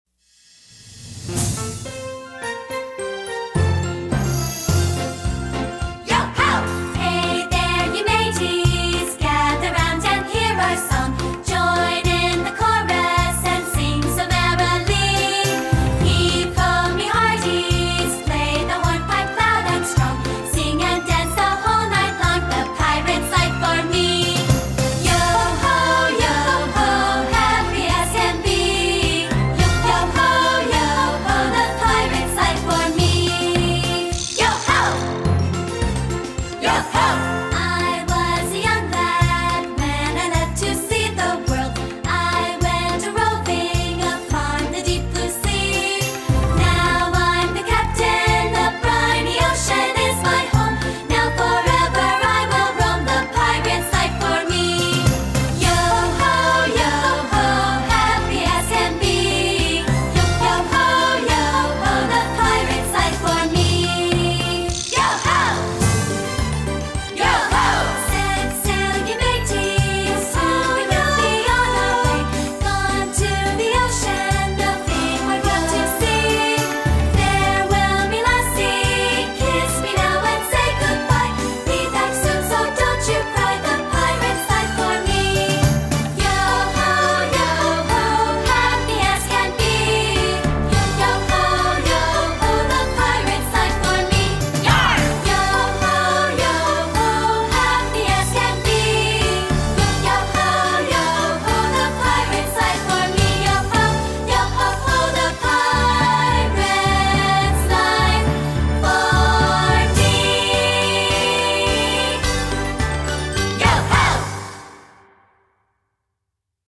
Voicing: TB and Piano